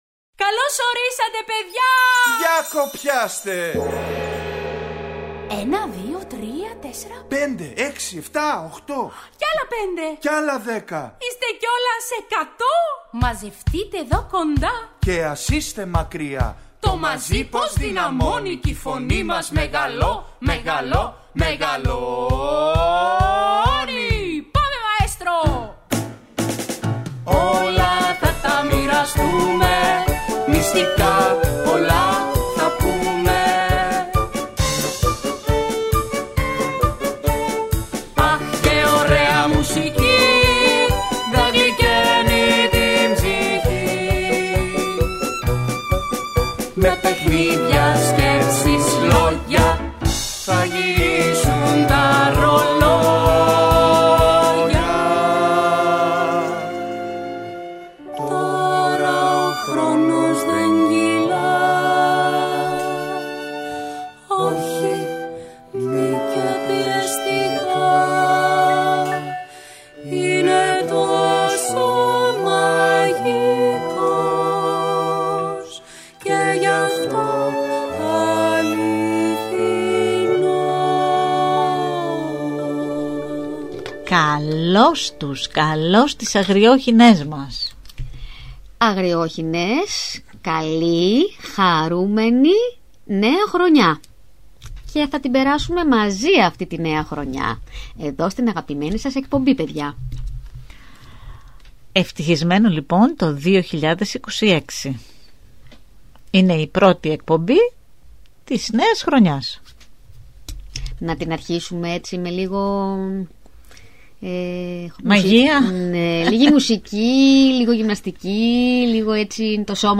Ακούστε στην παιδική εκπομπή ‘’Οι Αγριόχηνες’’ το παραμύθι « Το Κύμα των Αστεριών » της Dolores Brown.